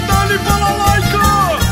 Play, download and share Balalaika original sound button!!!!
balalaika.mp3